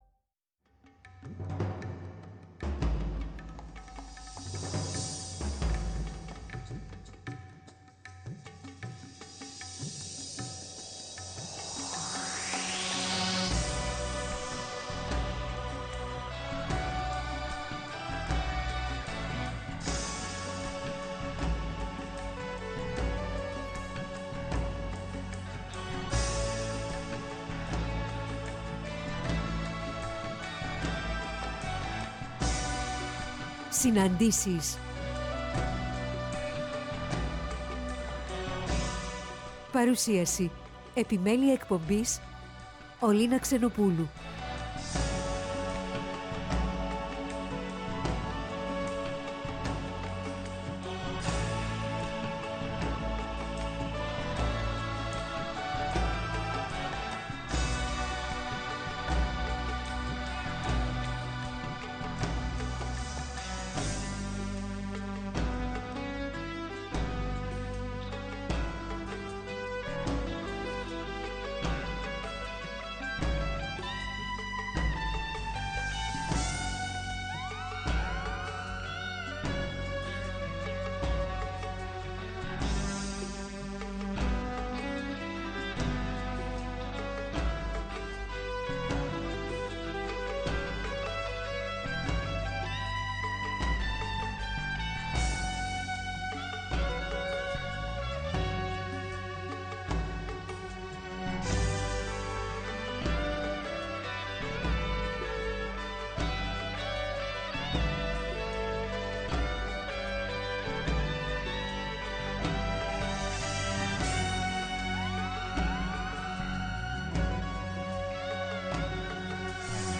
Αποκαλυπτικές Συναντήσεις με πρόσωπα της κοινωνικής, πολιτικής και πολιτιστικής τρέχουσας επικαιρότητας με τη μουσική να συνοδεύει, εκφράζοντας το «ανείπωτο».